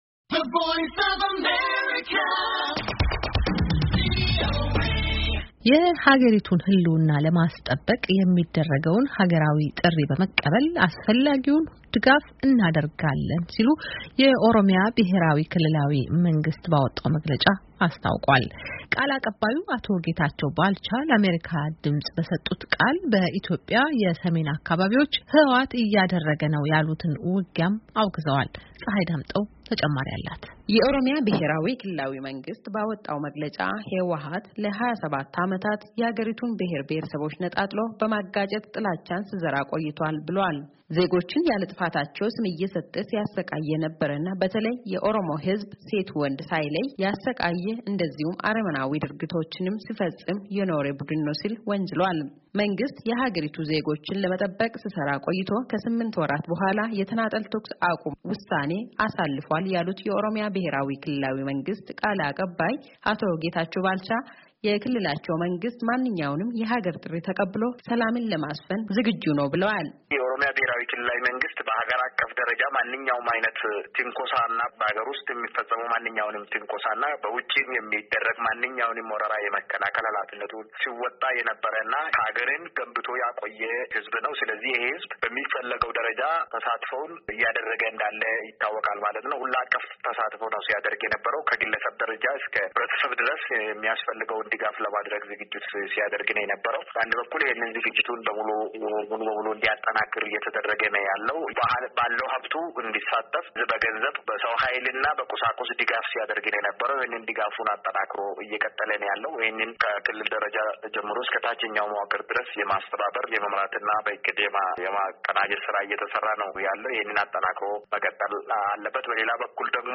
የህወሃት ቃል አቀባይ አቶ ጌታቸው ረዳ የትግራይ ግዛቶች ሙሉ በሙሉ እስኪመለሱ የክልሉ ኃይሎች የሚወስዱትን እርምጃ እንደሚቀጥሉ ተናግረዋል። “የፌዴራል መንግሥት ኃይሎች ከዚህ በኋላ የትግራይ ሥጋት እንዳይሆኑ ለማድረግ የሚያስችል ሥራ መሥራታችንን እንቀጥላለን” ሲሉ ከቪኦኤ ጋር በሳተላይት ስልክ በነበራቸው ቆይታ ገልፀዋል።